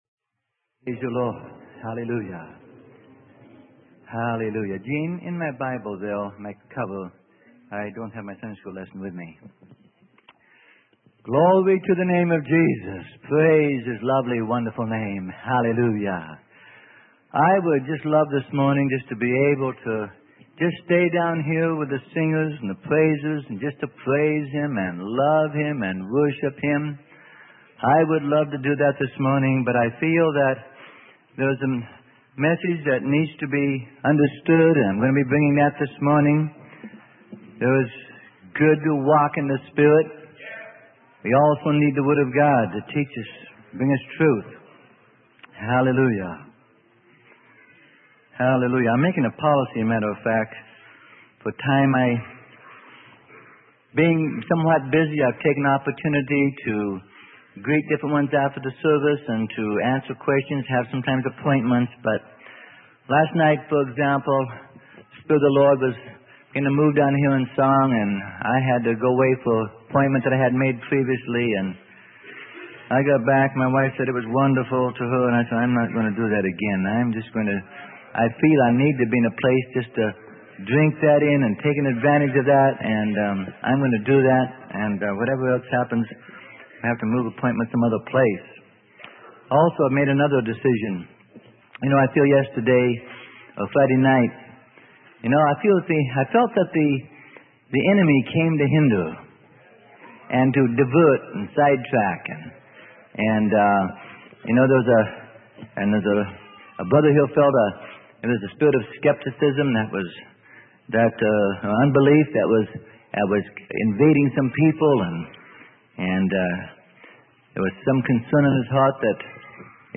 Sermon: Explaining Jesus Christ - Part 6 - Freely Given Online Library